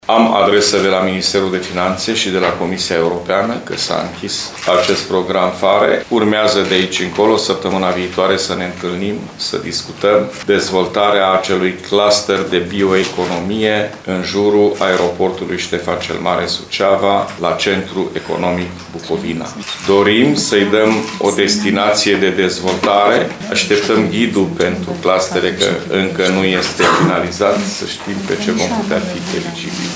Președintele Consiliului Județean Suceava, GHEORGHE FLUTUR, a declarat că schimbarea destinației spațiului poate fi posibilă de acum, după depășirea perioadei de monitorizare a programului PHARE.